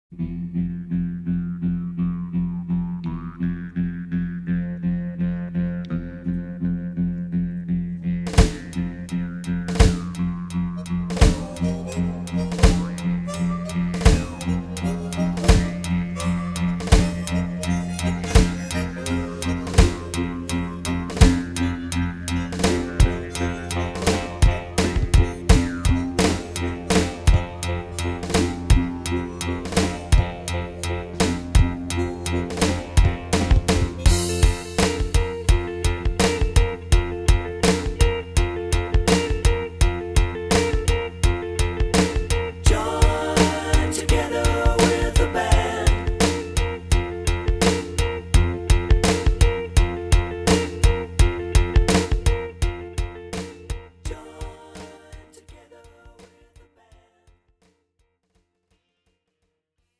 장르 pop 구분